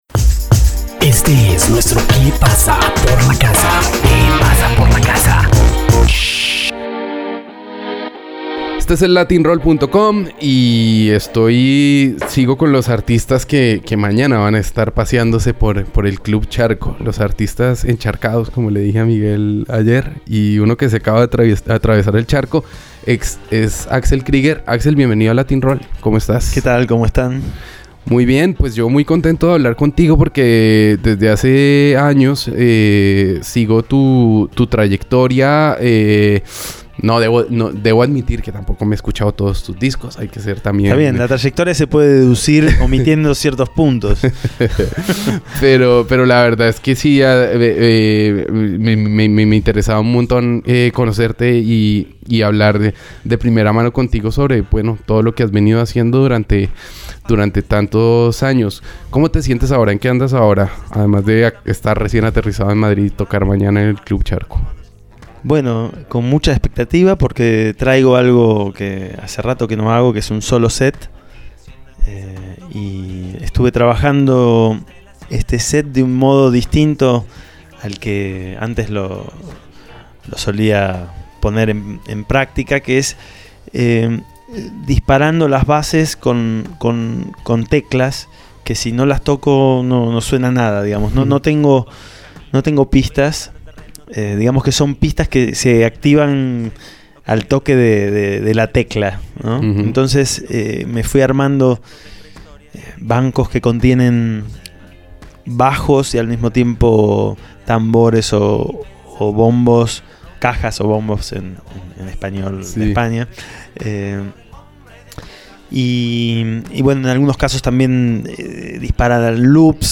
Latin-Roll - Entrevistas